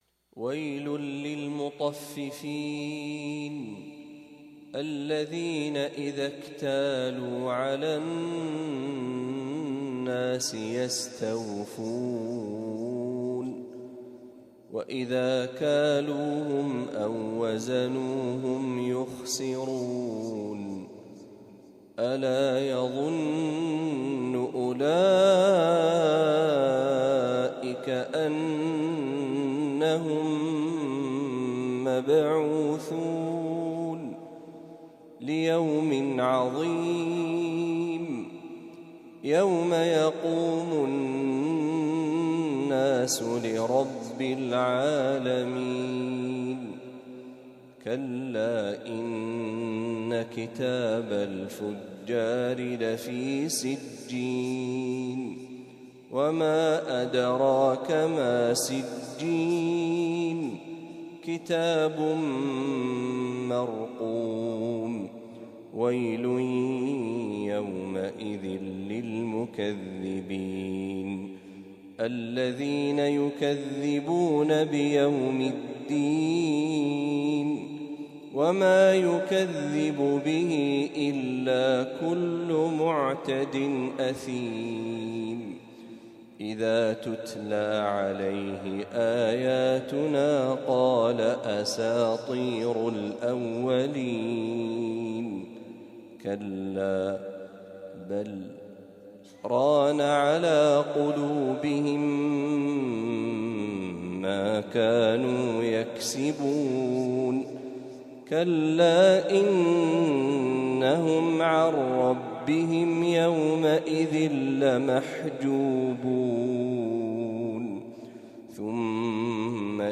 سورة المطففين كاملة | فجر الجمعة ١٣ محرم ١٤٤٦هـ > 1446هـ > تلاوات الشيخ محمد برهجي > المزيد - تلاوات الحرمين